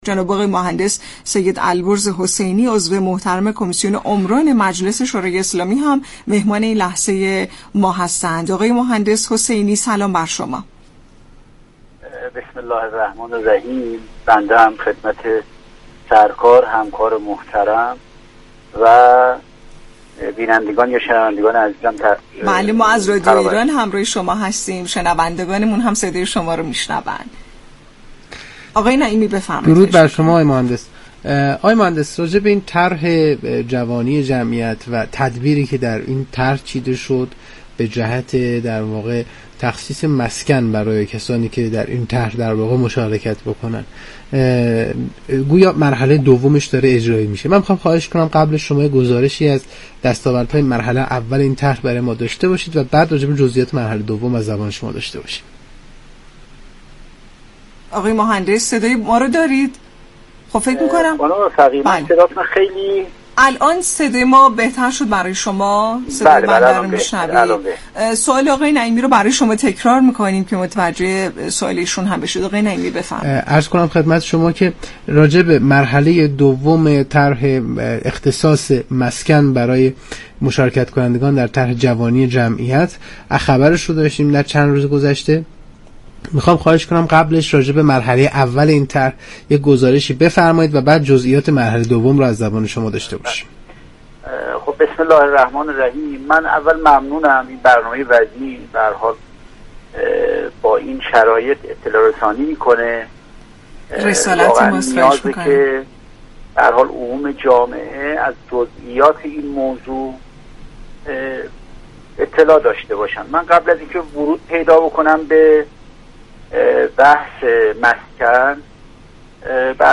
به گزارش شبكه رادیویی ایران، سید البرز حسینی عضو كمیسیون عمران مجلس در برنامه نمودار درباره مرحله دوم طرح جوانی جمعیت گفت: كشور با بحران سالخوردگی روبرو است و بحث مسكن و سایر موارد موجود در قانون جوانی جمعیت در واقع و به نوعی مشوق این طرح هستند.
برنامه «نمودار» شنبه تا چهارشنبه هر هفته ساعت 10:20 از رادیو ایران پخش می شود.